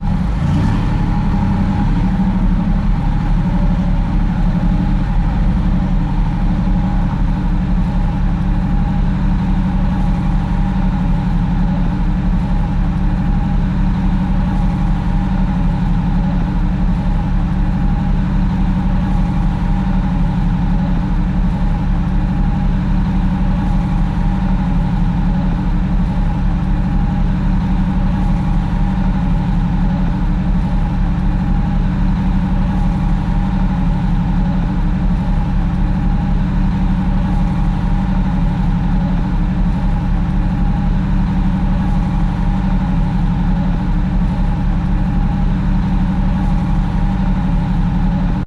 AAV Landing Craft | Sneak On The Lot
AAV landing craft idles at a low rpm. Vehicle, Landing Craft Engine, Idle